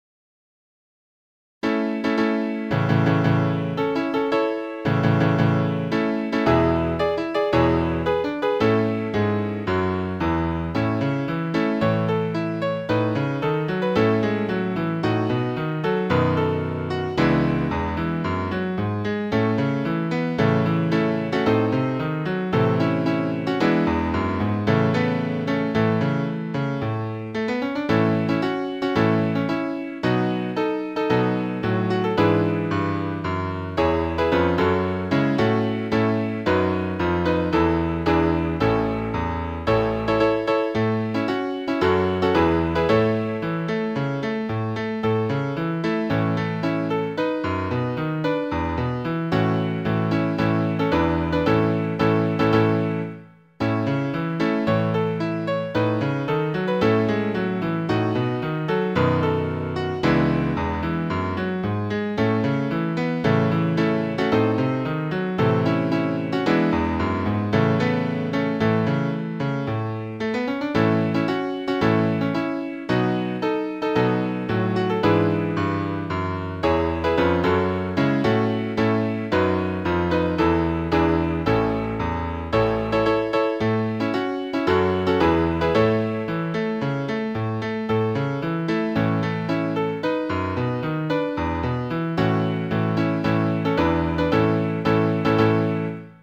校歌
作詞：勝　　承夫　　作曲：堀内　敬三
千歳北陽高校校歌（歌入り）.mp3